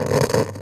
radio_tv_electronic_static_13.wav